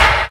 Index of /90_sSampleCDs/Roland L-CD701/SNR_Rim & Stick/SNR_Stik Modules
SNR CLOG 0EL.wav